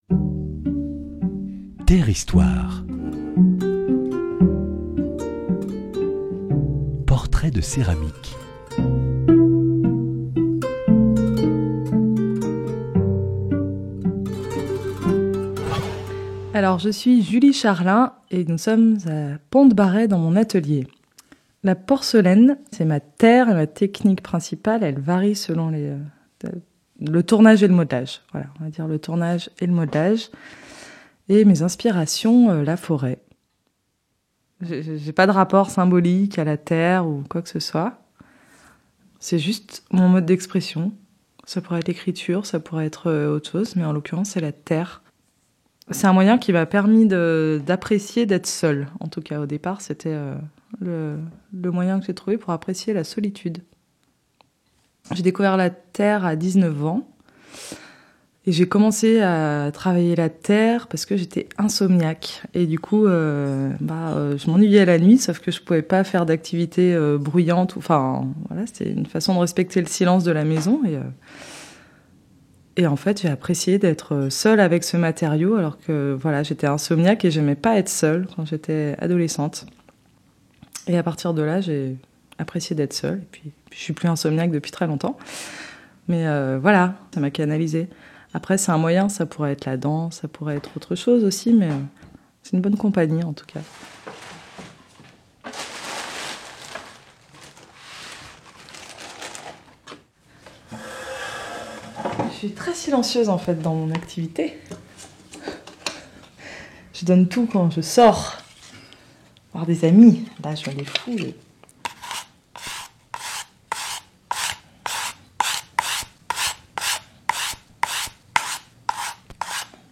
Dans le cadre du 14e marché des potiers de Dieulefit, portraits de céramiques en atelier: matériaux, gestes créatifs, lien avec la matière, 8 potiers évoquent la terre de leur quotidien….